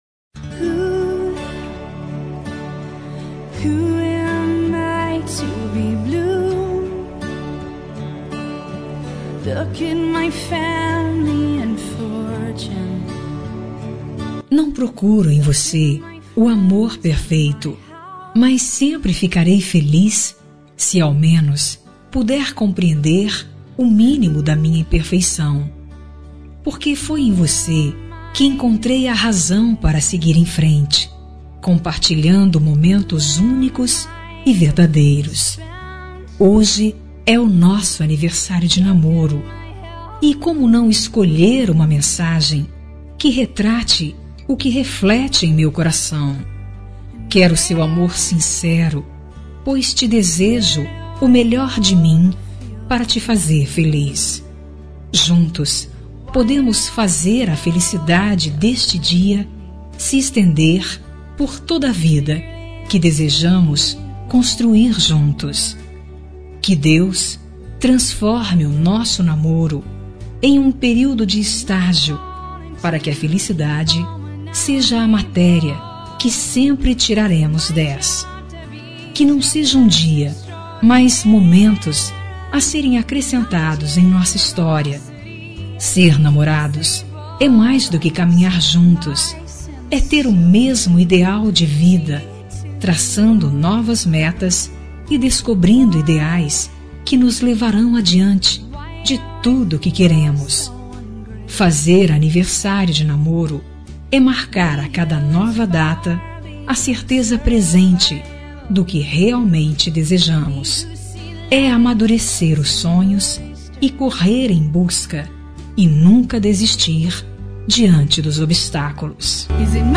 Telemensagem Aniversário de Namoro – Voz Feminina – Cód: 010001